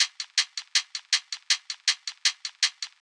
DDW Hat Loop.wav